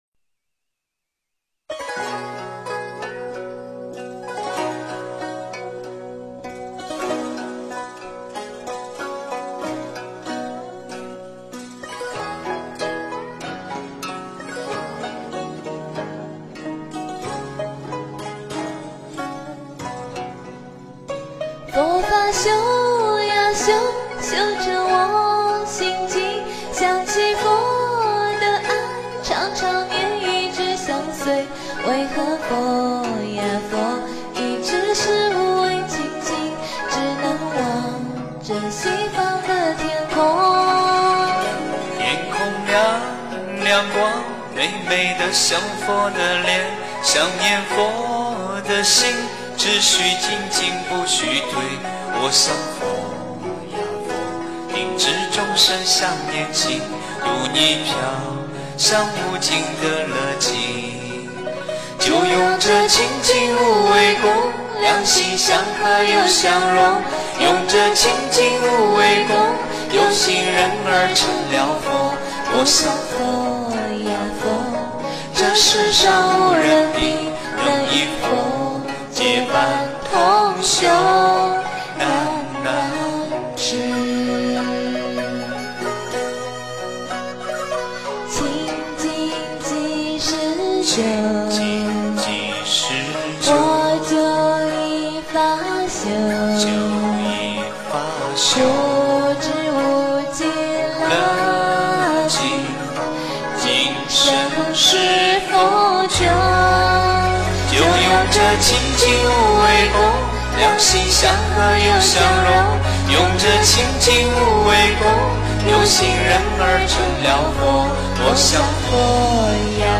诵经
标签: 佛音诵经佛教音乐